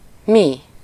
Ääntäminen
IPA: /ˈmi/